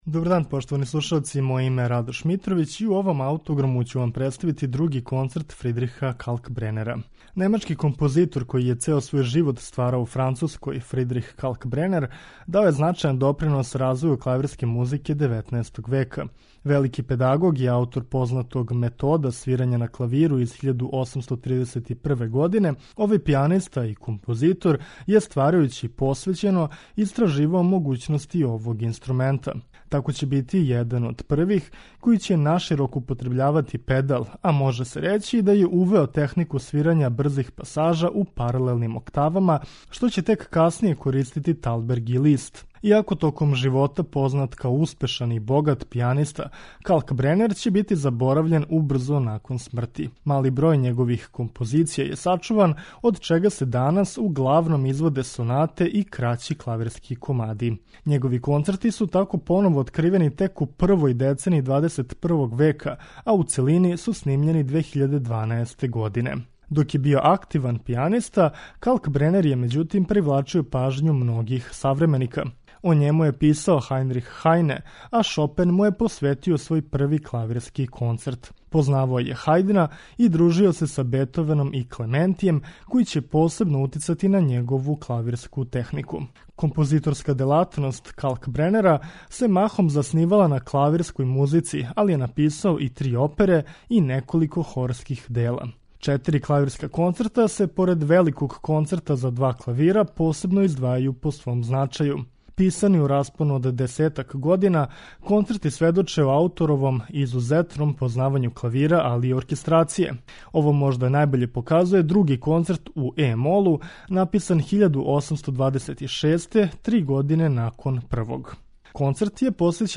Други клавирски концерт из 1826. године сведочи о Калкбренеровом пијанистичком искуству и веома добром познавању могућности клавира.